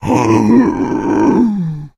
fracture_die_2.ogg